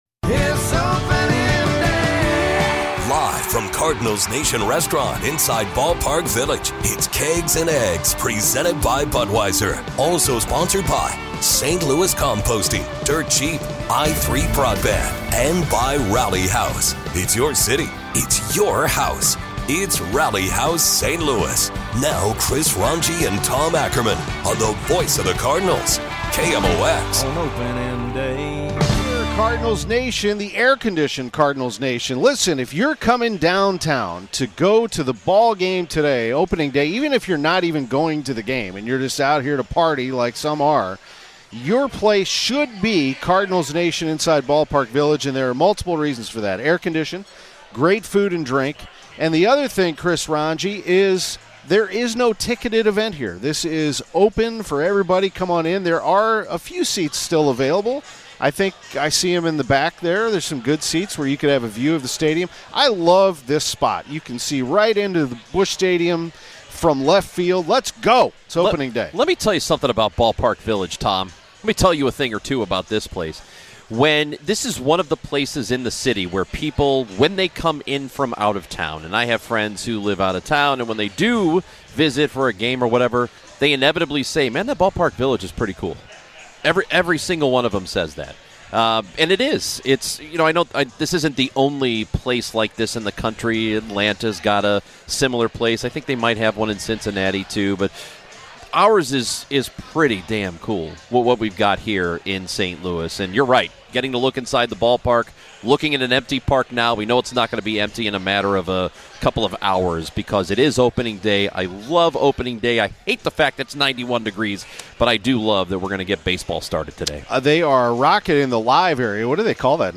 The Gashouse Gang- Live from Cardinals Nation for Opening Day